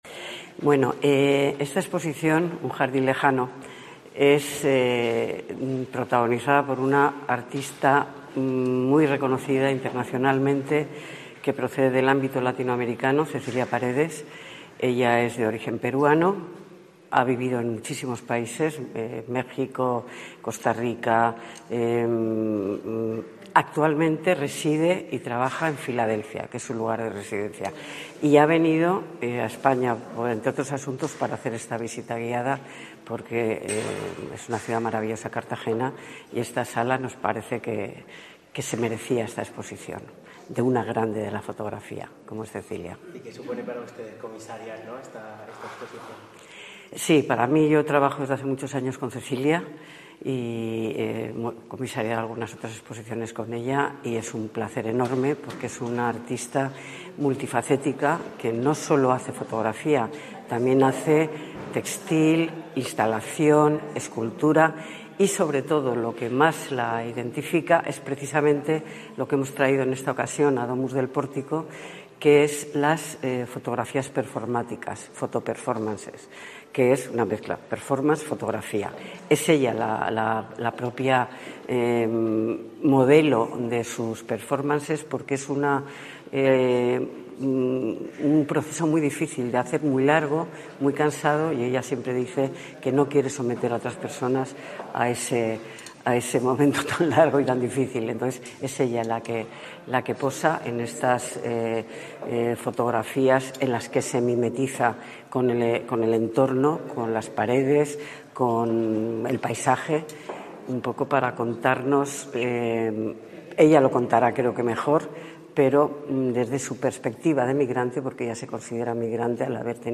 La artista ha compartido con el público asistente los detalles de su muestra, que estará en la Sala Domus del Pórtico hasta el 20 de abril
Cecilia Paredes realiza una visita guiada a su exposición 'Un jardín lejano'